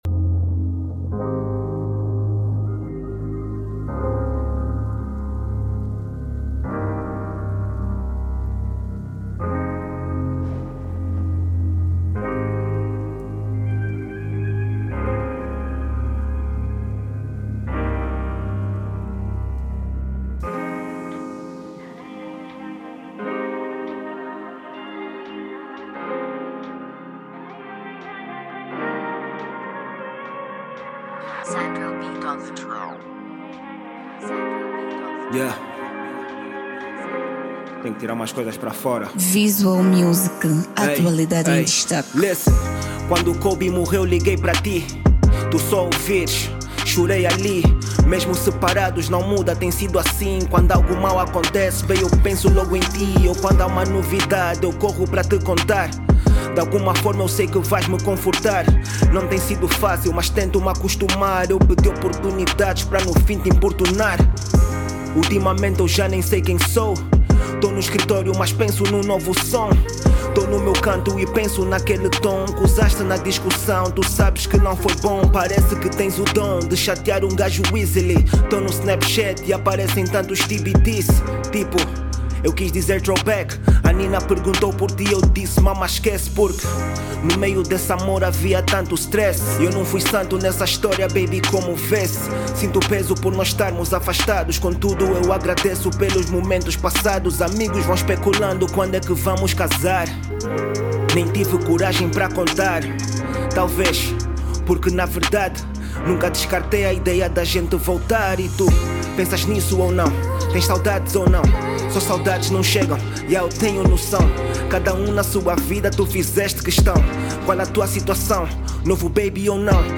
Género: R&b